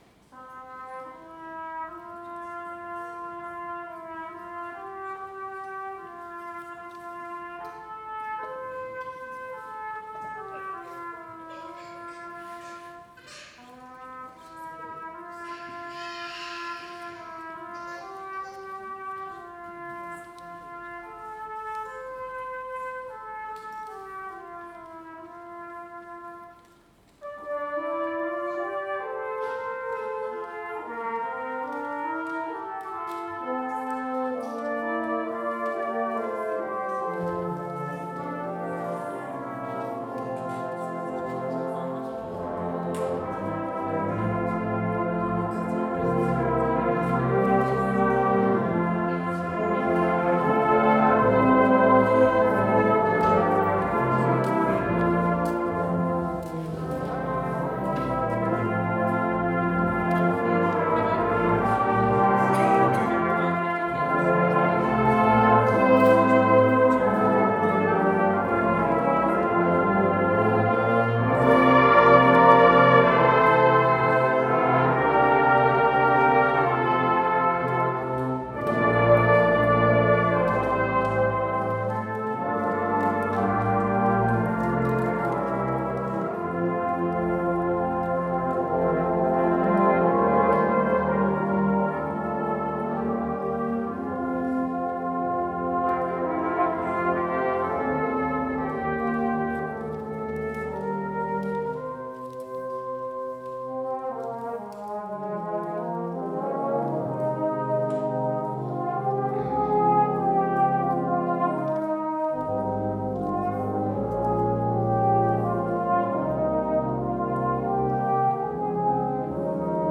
Somersham Carols by Candlelight Christmas Concert 2019 - Somersham Town Band
These recordings were taking during the 'live' performance and the Microphones were set close to the audience. Because of this you will hear a little background noise and some very enthusiastic audience participation!